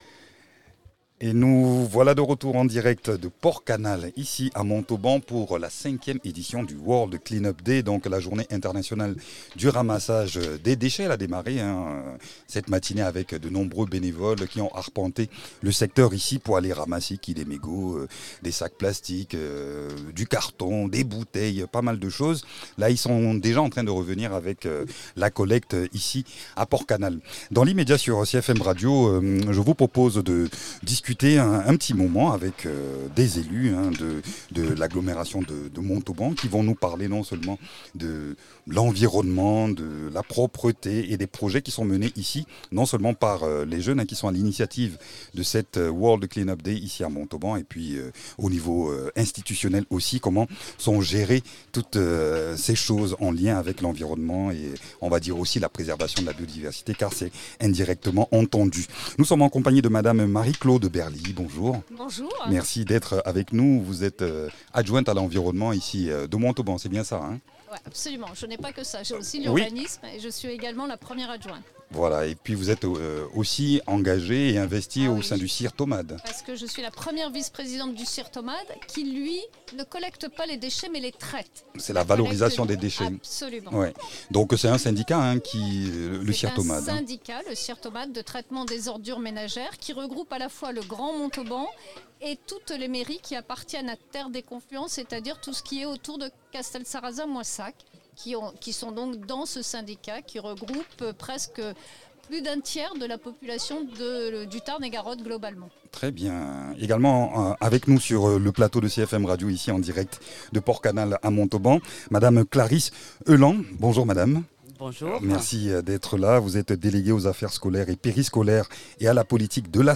Magazine en direct de la 5ème édition du World clean up day, depuis Port-Canal à Montauban.